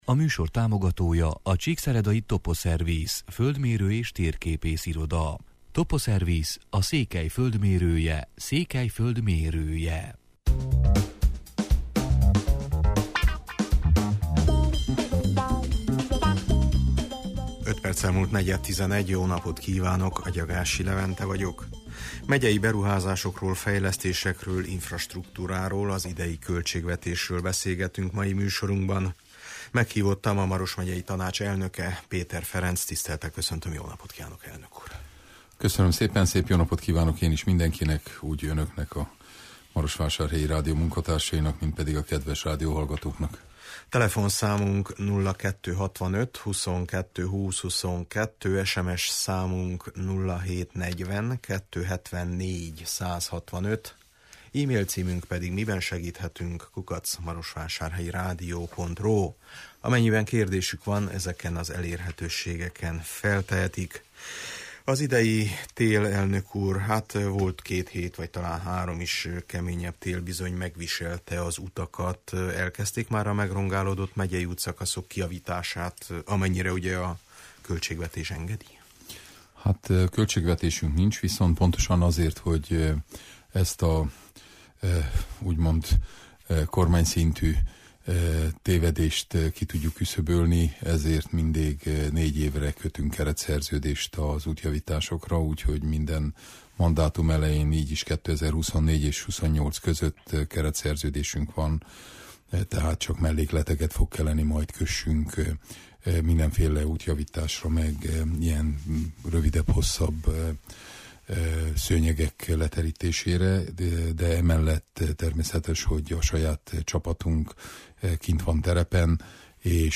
Meghívottam a Maros Megyei Tanács elnöke, Péter Ferenc: